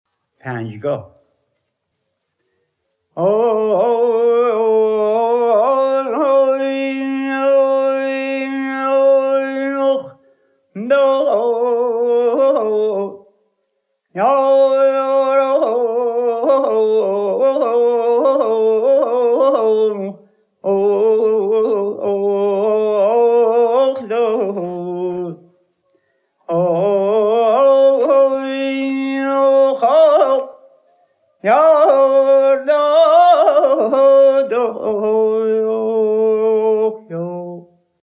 نمونه ای از خوانده ی عبداله خان دوامی در دستگاه راست پنجگاه
radif-abdollah-davami-panjgah-rast.mp3